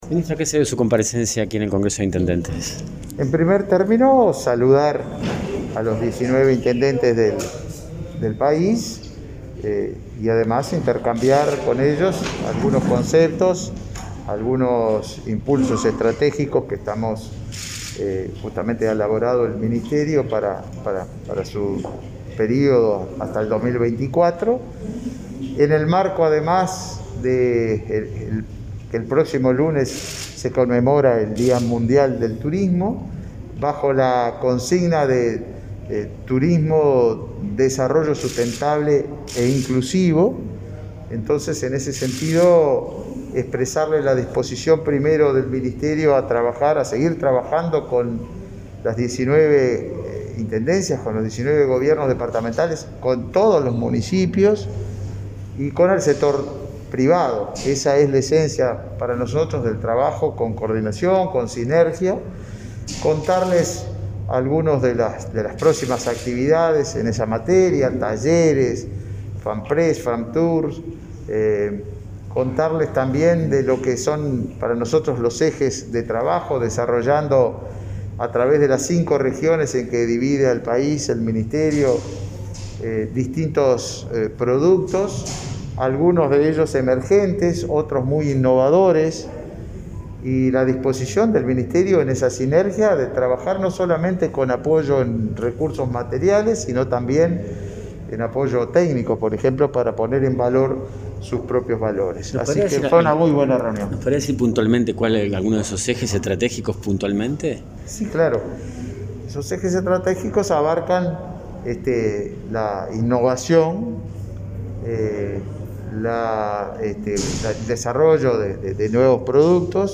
Declaraciones del ministro de Turismo, Tabaré Viera, a la prensa
Tras participar en el Congreso de Intendentes, este 23 de setiembre, el ministro Viera efectuó declaraciones a la prensa.